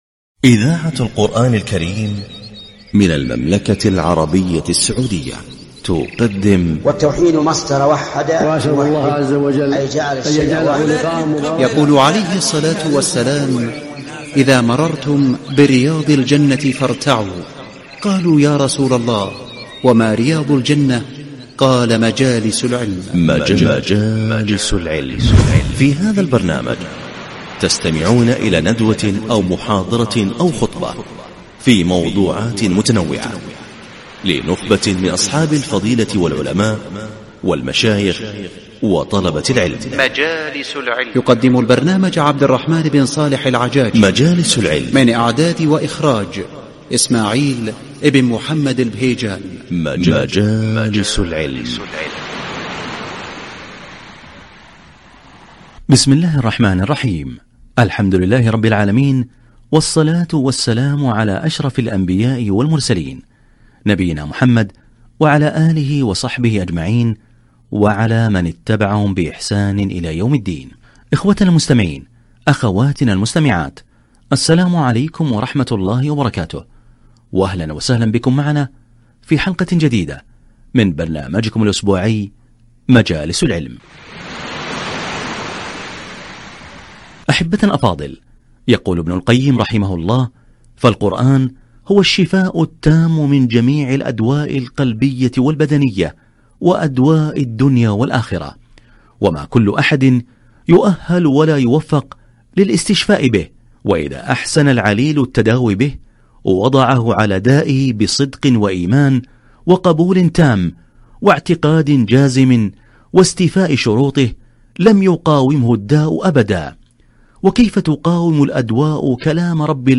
الاستشفاء بالقرآن محاضرة - البرنامج الإذاعي مجالس العلم